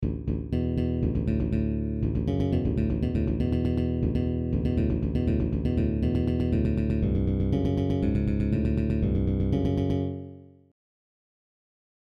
Bass+Riff+6.mp3